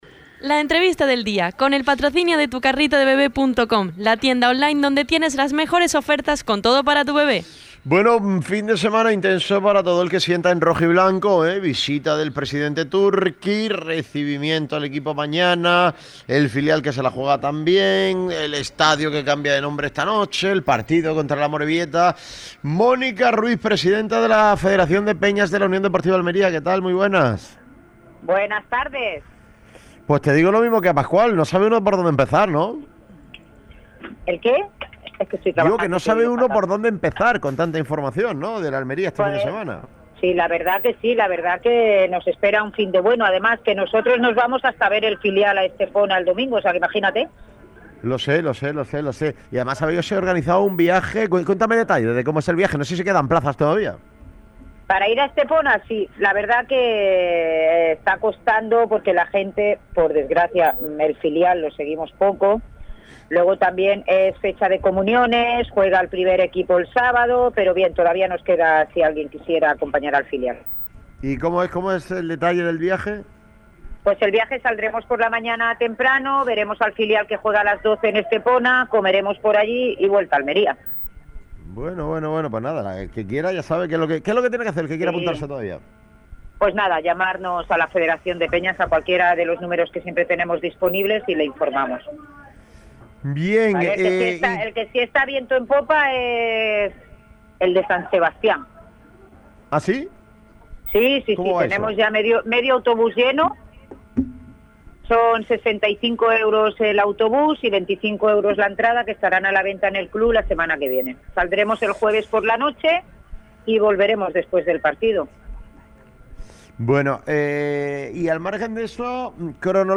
LA ENTREVISTA DEL DÍA